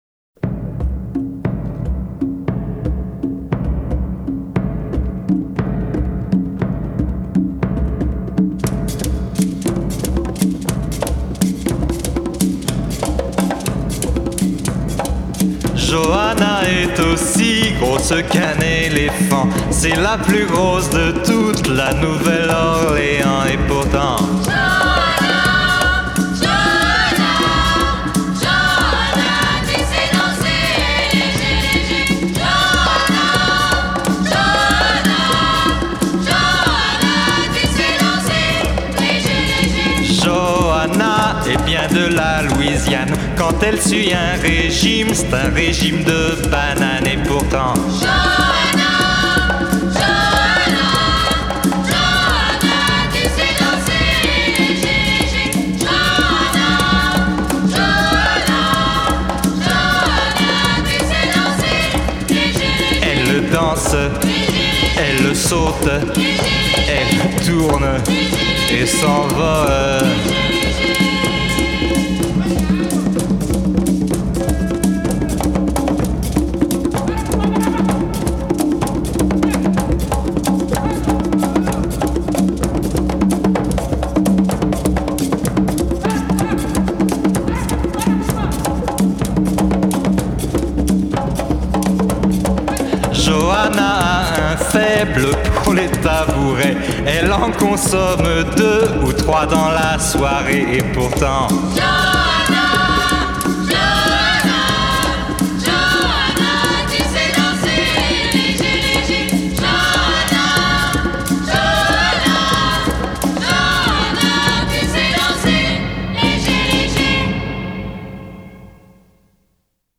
rythmes africains